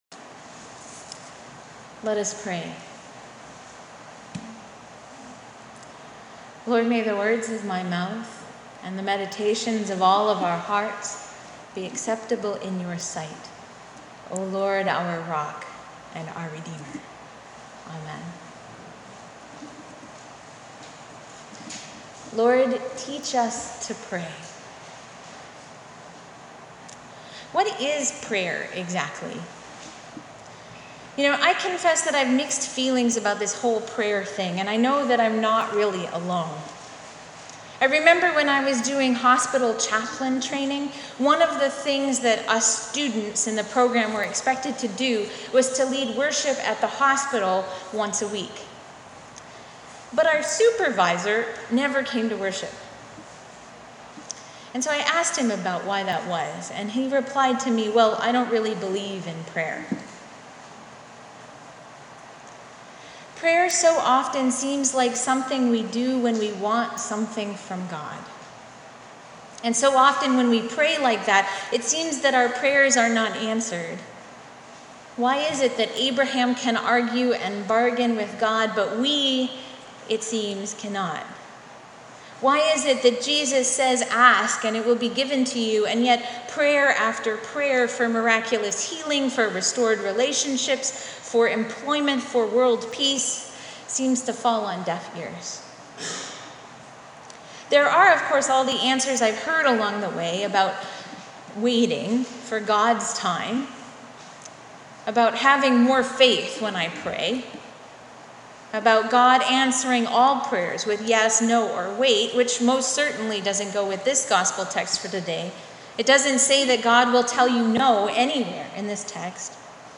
10th-sunday-after-pentecostc.mp3